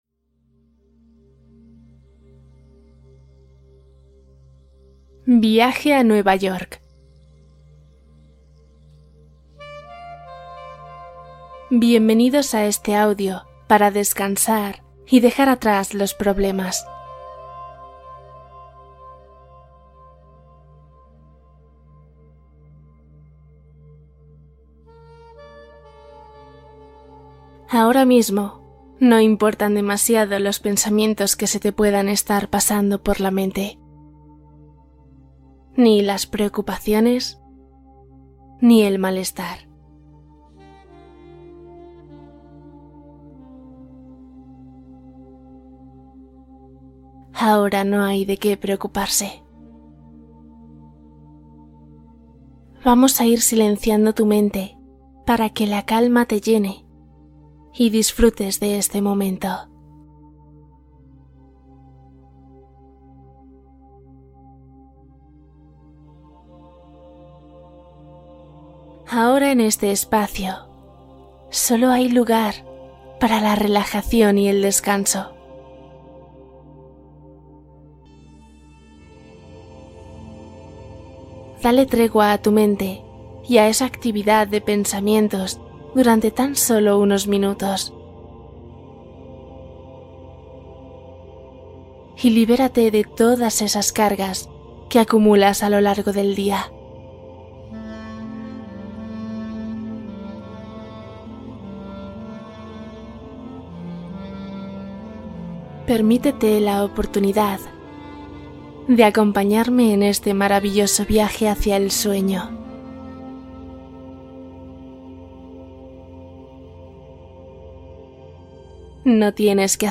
Cuento para dormir — viaje nocturno a Nueva York